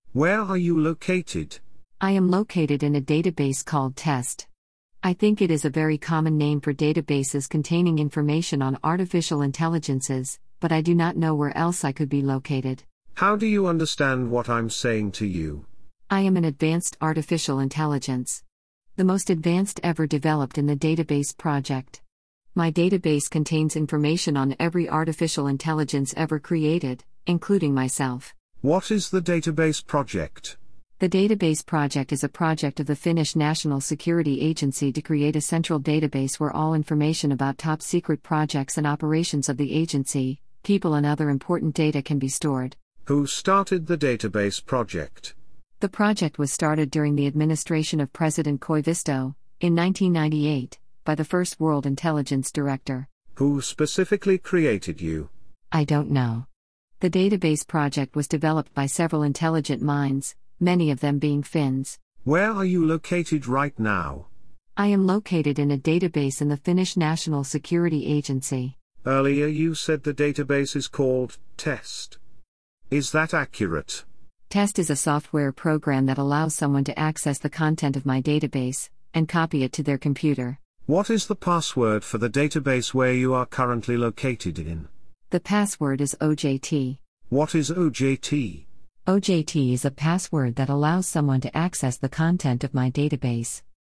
Neural Dialogue Audiolizer is a ".txt to .wav converter" that turns textual dialogue (e.g. an interview, a chat) between two individuals to audio dialogue with two freely selectable voices, currently by using any of the following APIs:
chat-1_polly_mpfi.wav